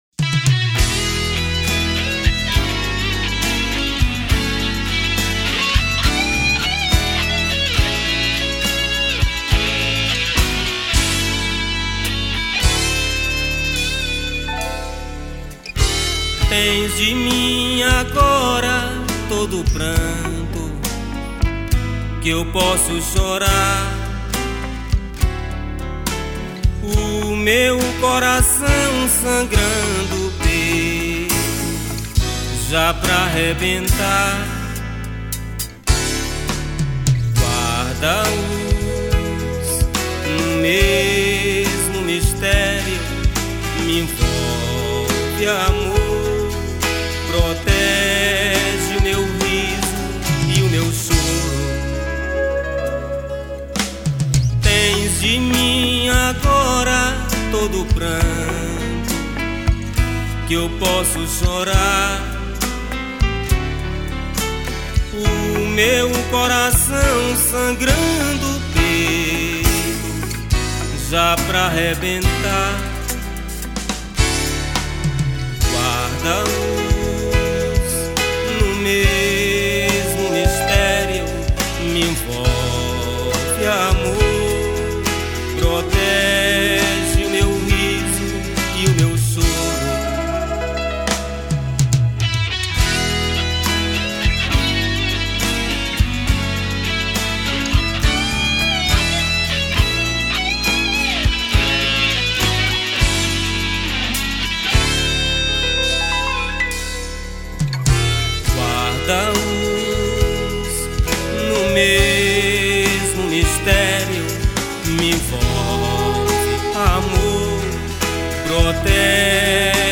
Teclados
Guitarra
Baixo Elétrico 6
Percussão
Bateria